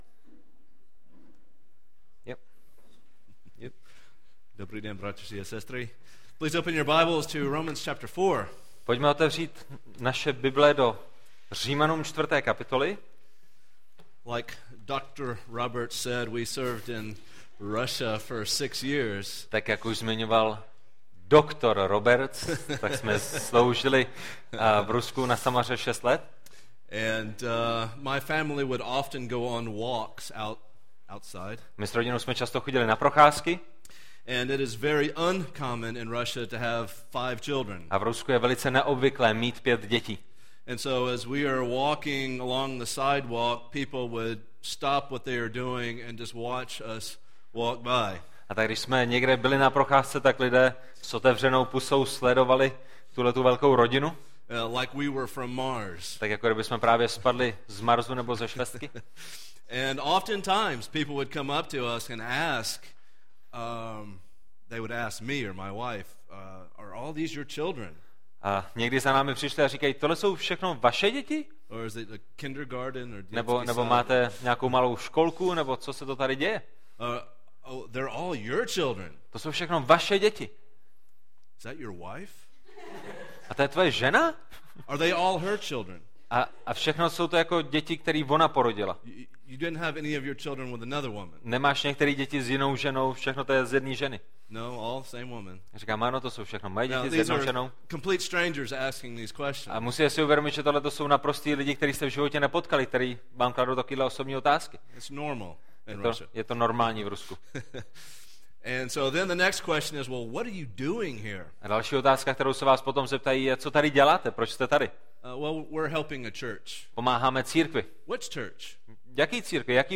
17 září, 2016 | 2016 / 2017, Audio, Média, Studentské bohoslužby |